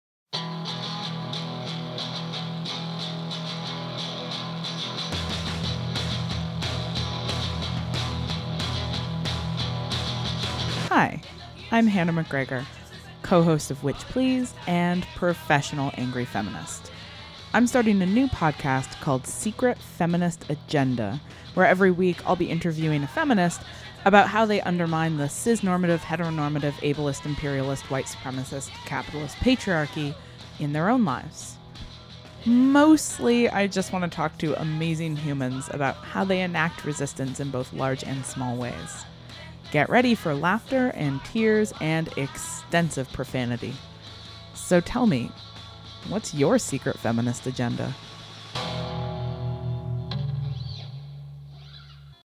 Get ready for laughter and tears and extensive profanity.
Theme song is Mesh Shirt by Mom Jeans off their album Chub Rub.